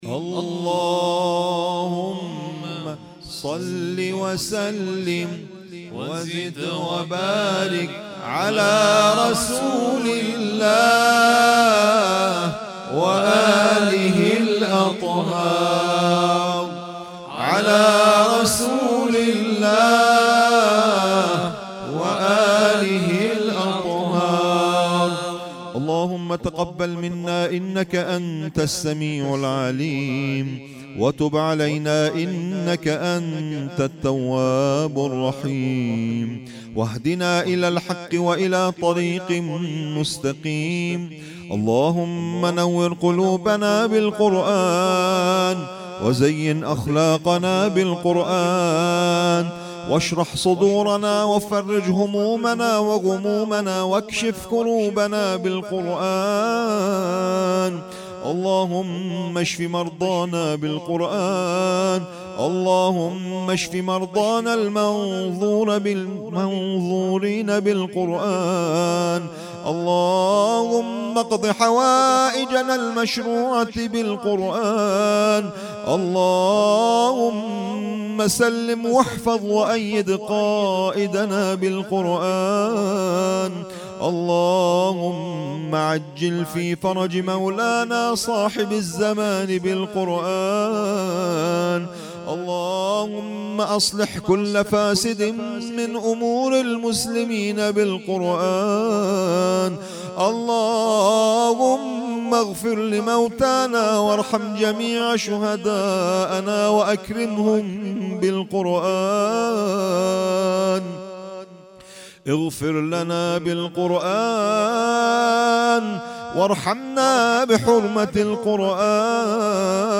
ترتیل خوانی جزء ۱۲ قرآن کریم - سال ۱۴۰۳